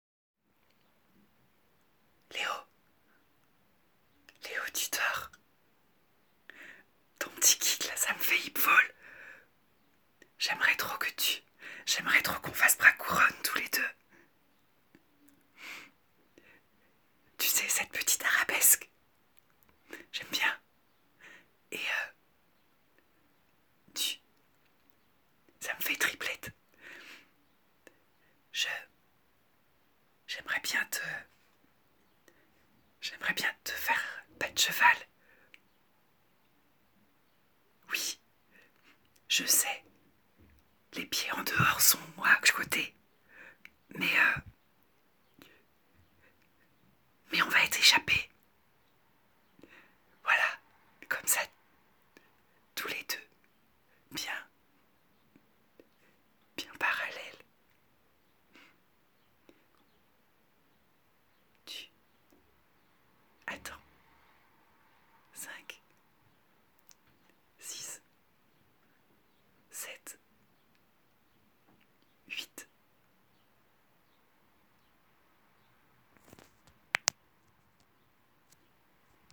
Des fragments de "théâtre improvisé" immatériels, basés sur les relations textuelles.